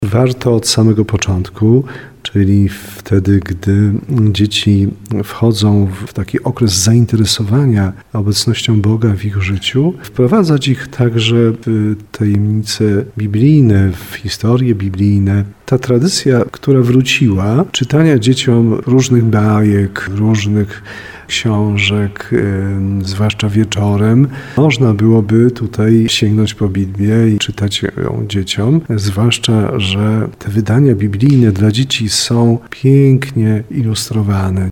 Biblia to najważniejsza księga ludzkości – mówi biskup tarnowski Andrzej Jeż.